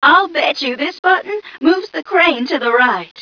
1 channel
mission_voice_m1ca053.wav